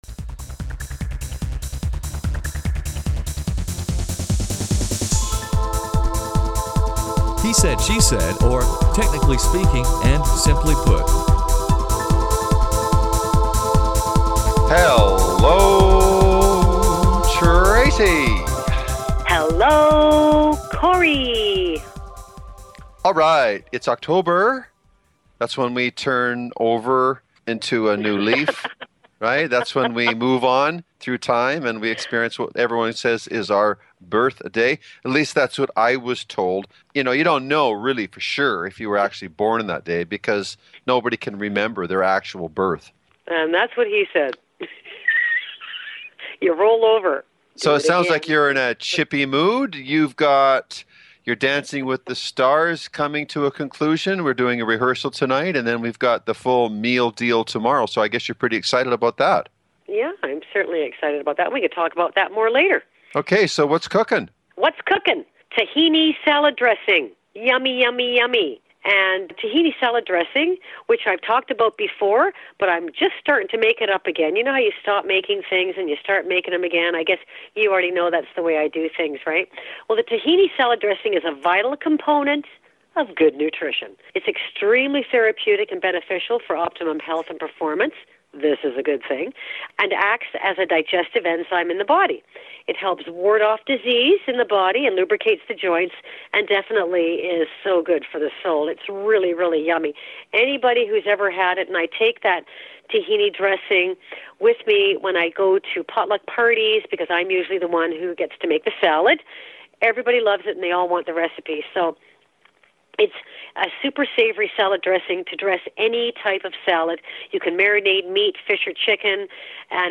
He-Said-She-Said Volume 15 Number 10 V15N10b - Topics What’s Cookin? Tahini Salad Dressing Hot Topic: Travel Pack He-Said-She-Said is a spontaneous and humorous dialog